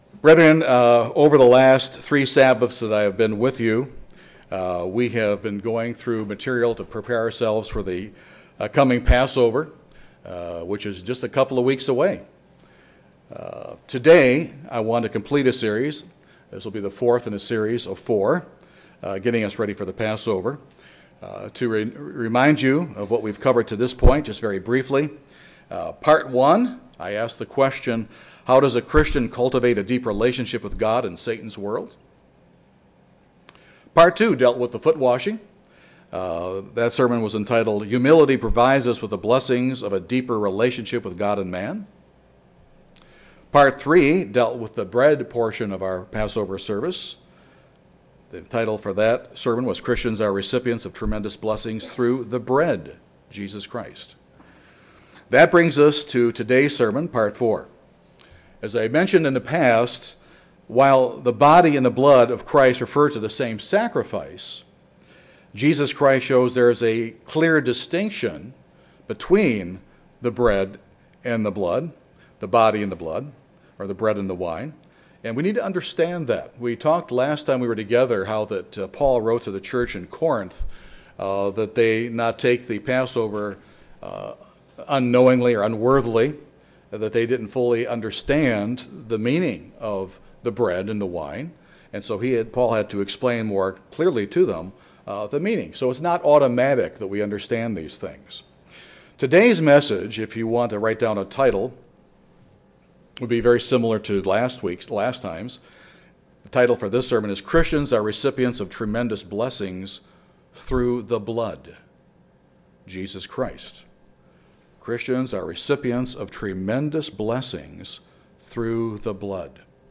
This is the last sermon in a four part series preparing us for the Passover.